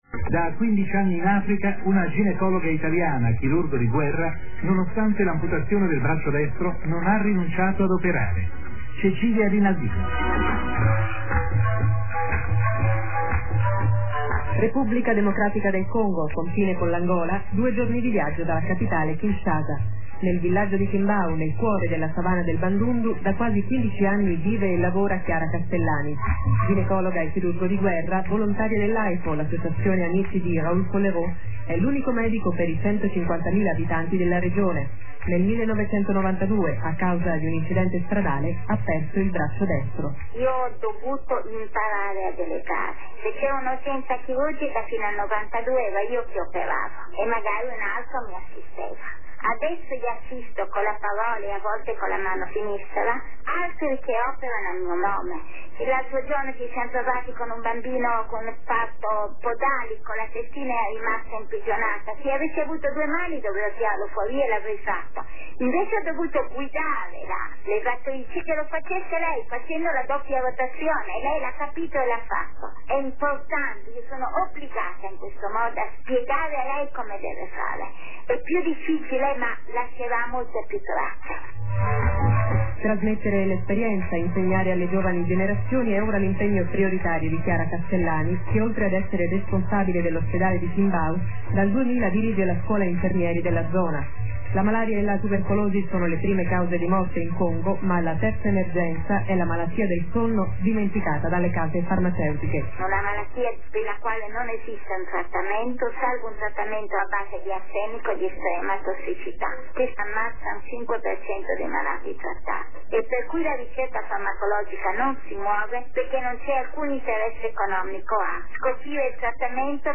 Intervista radiofonica
La parte musicale è un po' meno "fedele" ma il file è ancora più leggero. Invariato rimane il sonoro della voce.